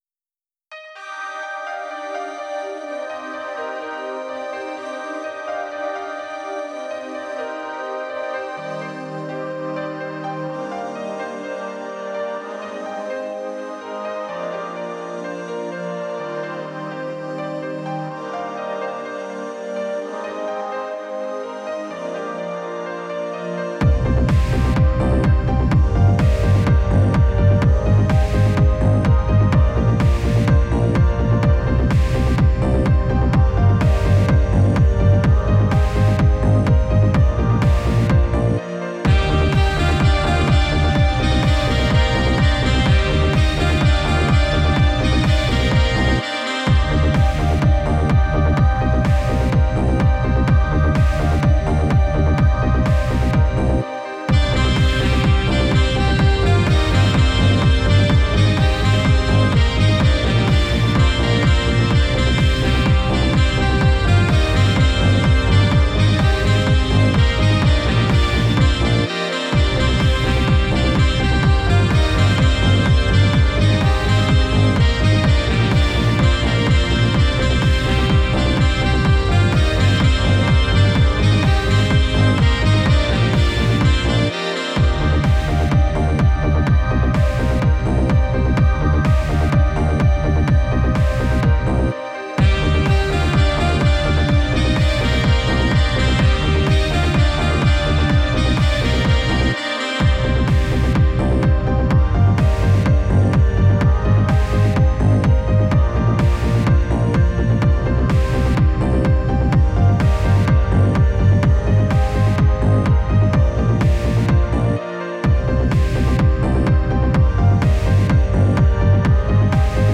BEST ELECTRO G-Q (39)